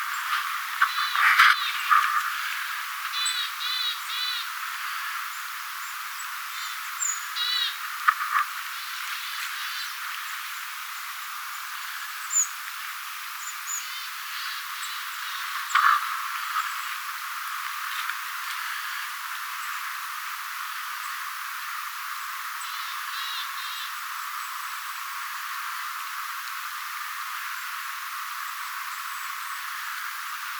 hömötiaislintu huomioääntelee
puun latvuksessa
homotiainen_huomioaantelee_puun_latvuksessa.mp3